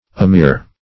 Amir \A*mir"\, n.